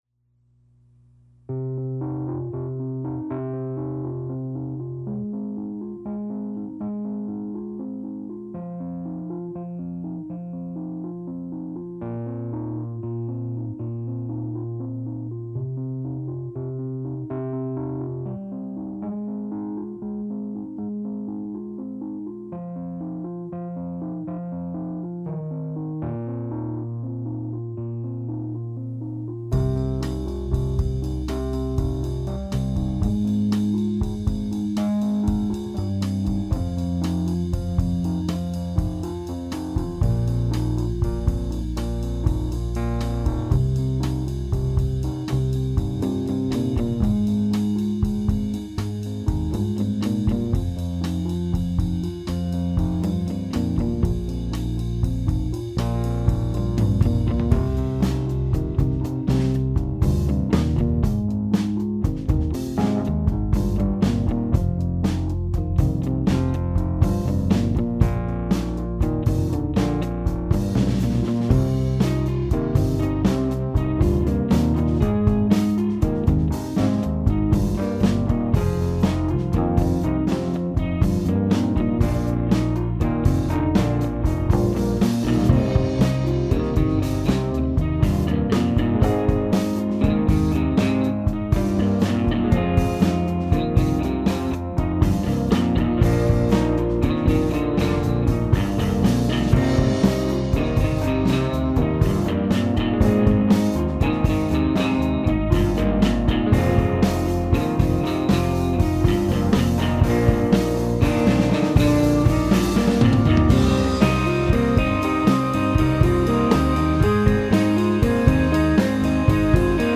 4 instruments, 4 chords, 4 minutes…